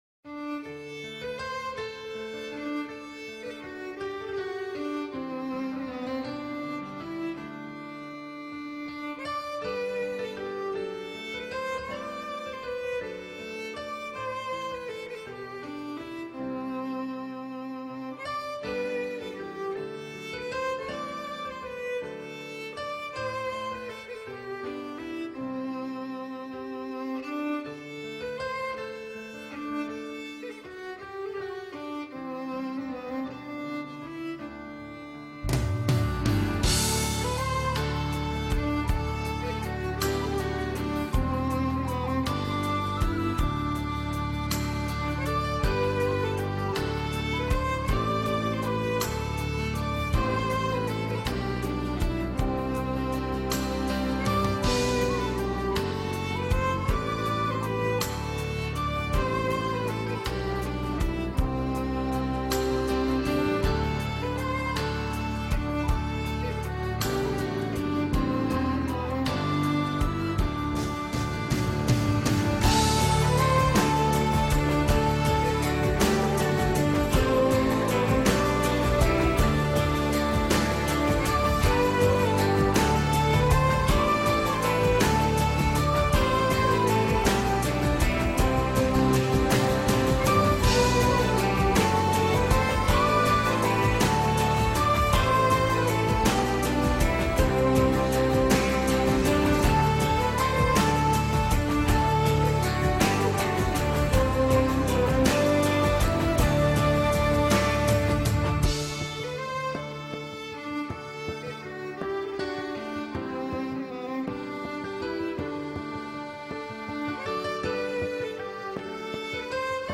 American Viennese Waltz